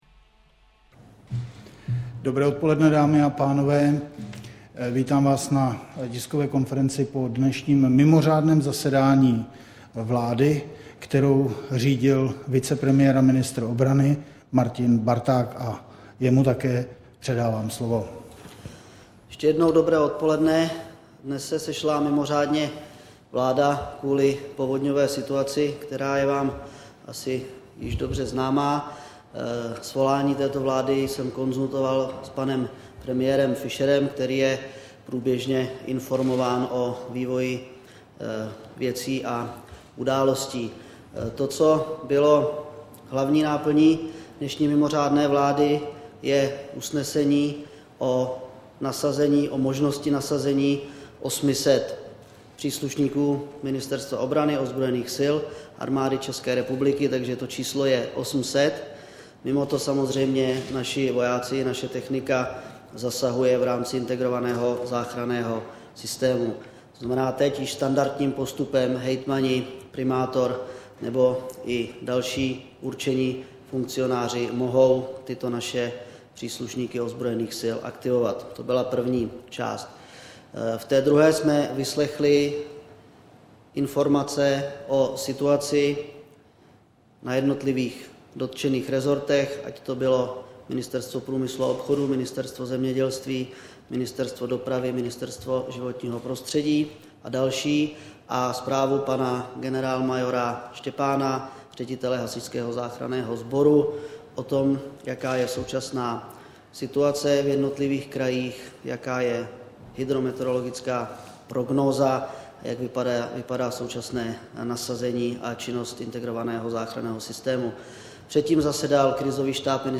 Tiskový brífink po mimořádném zasedání vlády, 17. května 2010